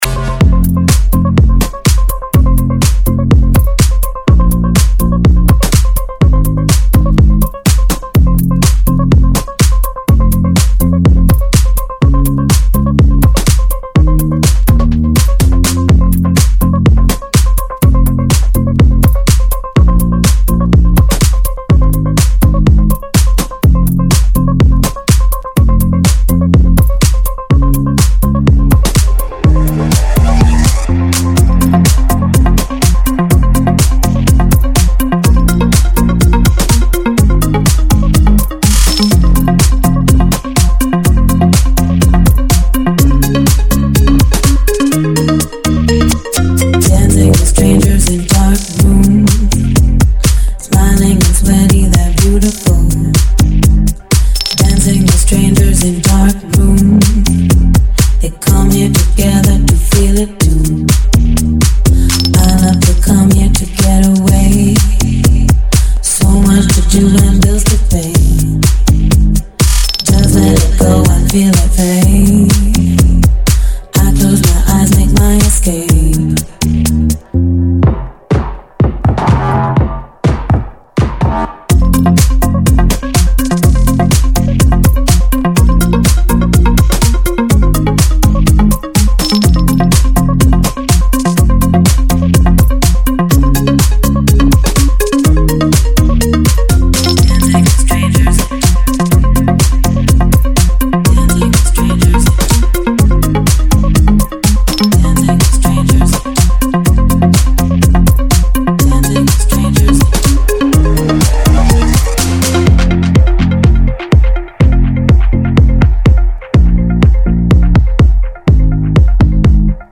最新HIGH歌，车载听也不错，震撼